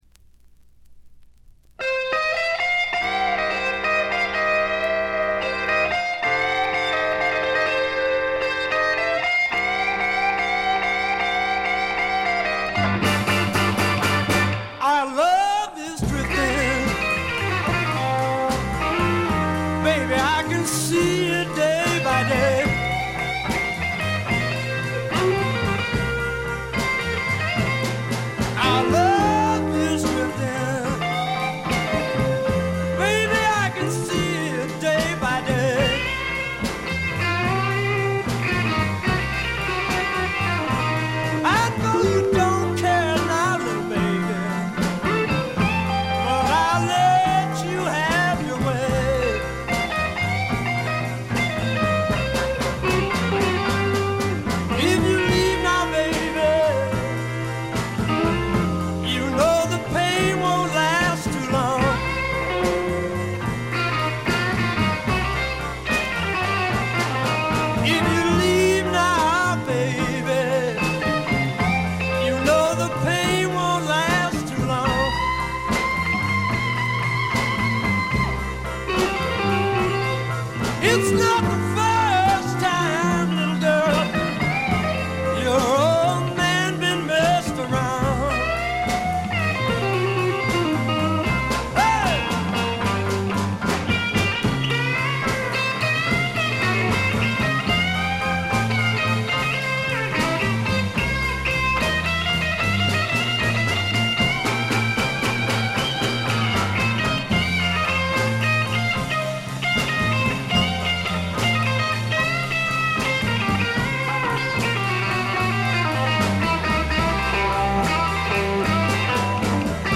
暴風雨のような凄まじい演奏に圧倒されます。
試聴曲は現品からの取り込み音源です。